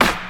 Royality free snare drum sample tuned to the G note. Loudest frequency: 1641Hz
• '00s Short Hip-Hop Steel Snare Drum Sample G Key 32.wav
00s-short-hip-hop-steel-snare-drum-sample-g-key-32-X3B.wav